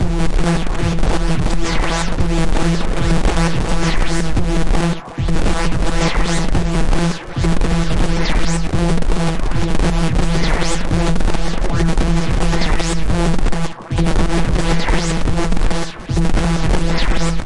描述：抽象故障效果/使用Audacity和FL Studio 11制作
Tag: 电气 怪异 音响设计 效果 设计 毛刺 随机 摘要 科幻 声音